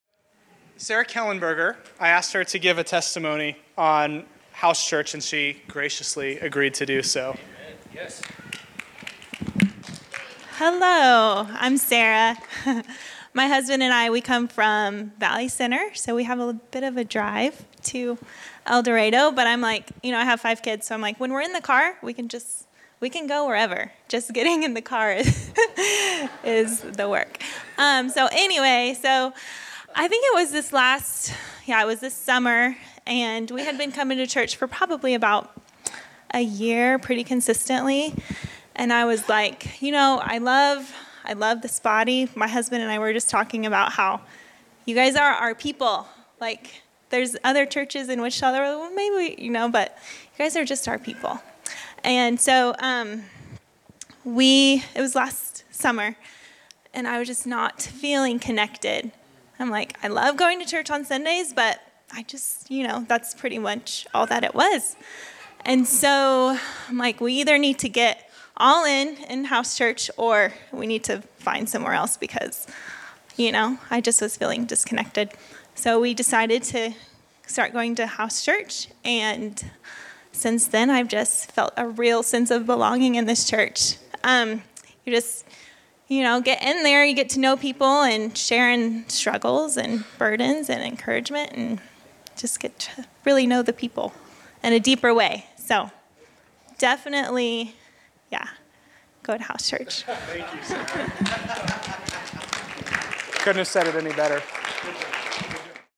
Category: Exhortation      |      Location: El Dorado